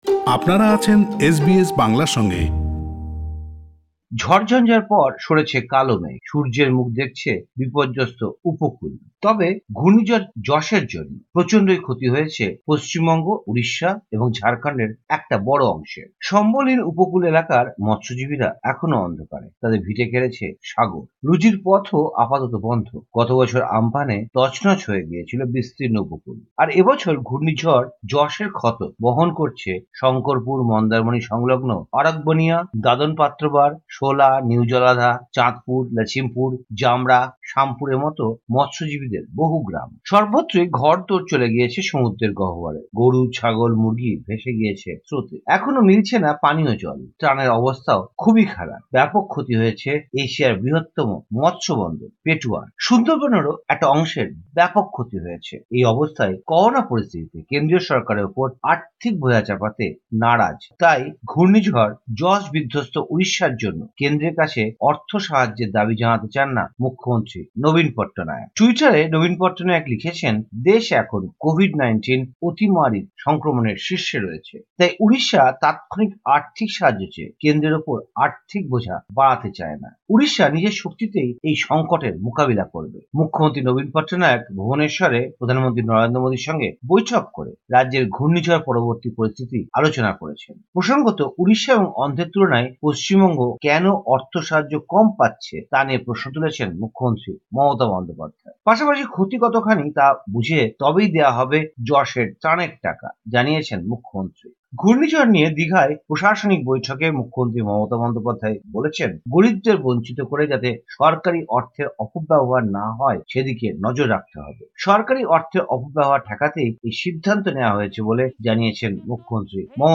ভারতের সাম্প্রতিক ঘটনাগুলোর খবর শুনতে উপরের অডিও প্লেয়ারটিতে ক্লিক করুন।